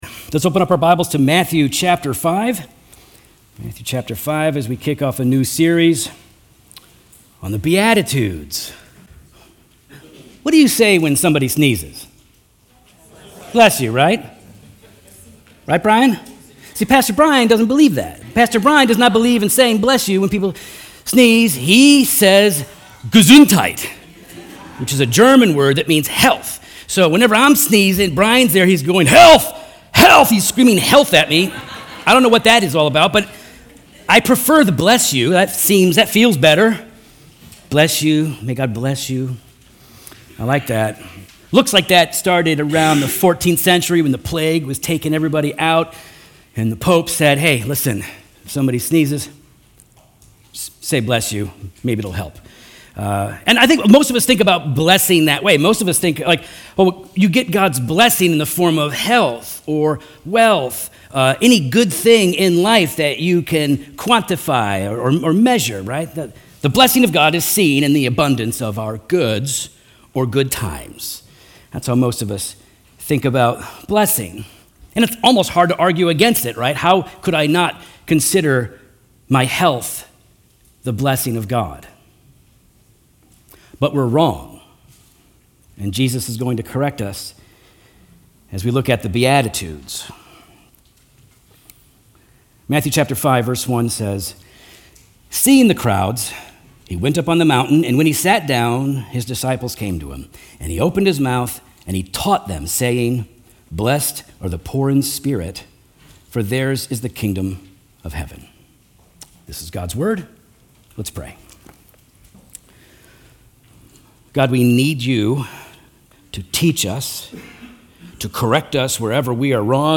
Redeemer Fellowship Sermons Podcast - The Poor in Spirit | Free Listening on Podbean App